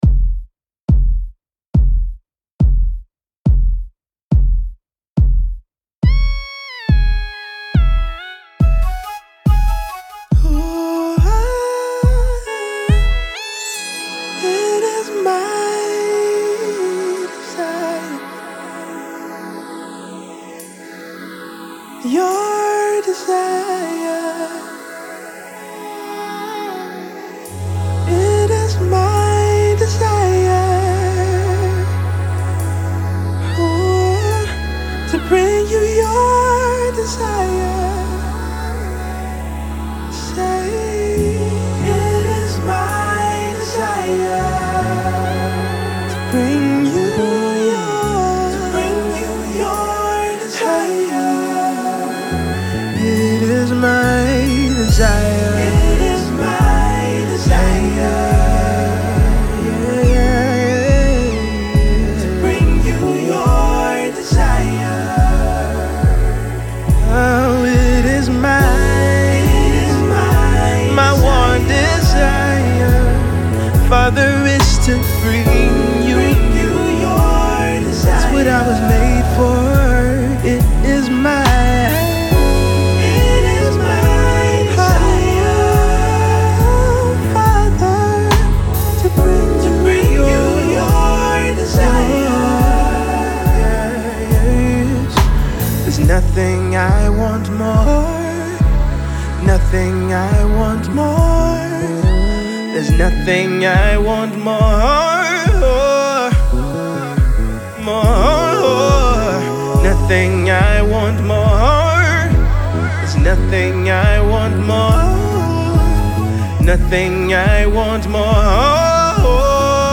Gospel music artiste